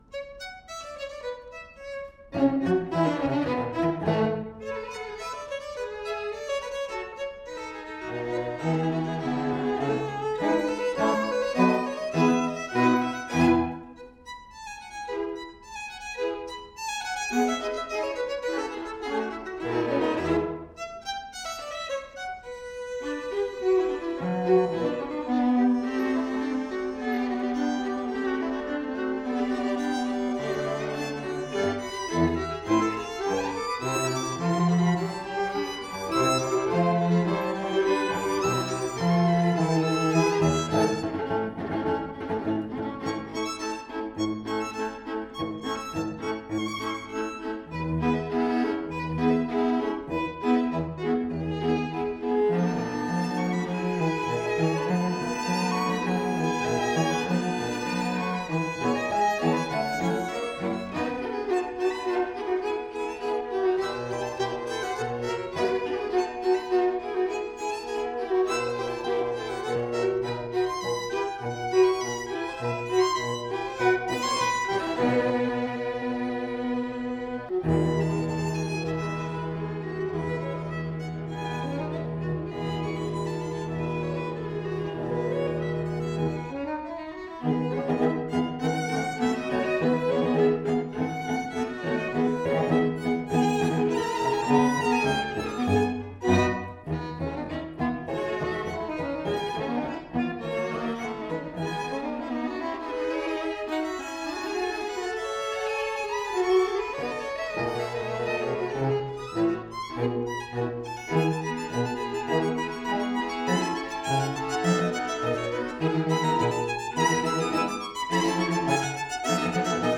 Soundbite 4th Movt
For 2 Violins, 2 Violas and Cello